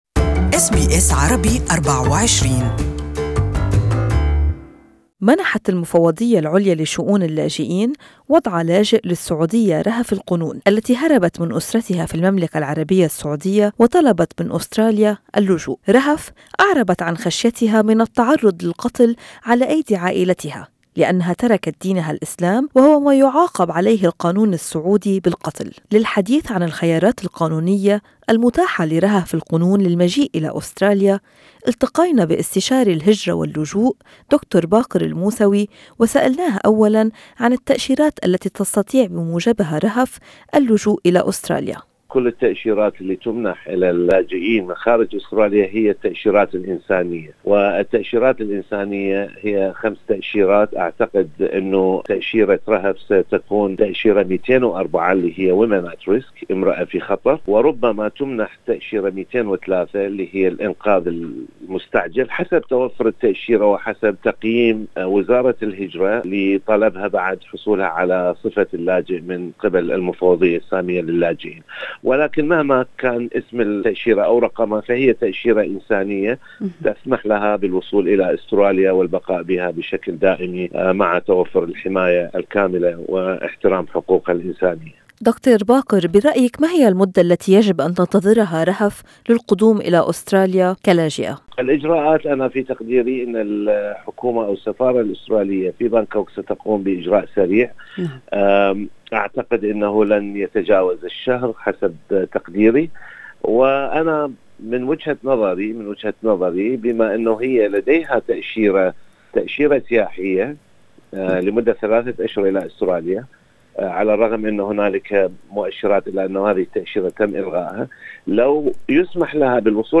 Listen to the interview in Arabic above.